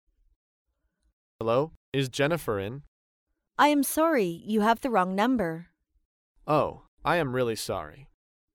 dialogue
英语情景对话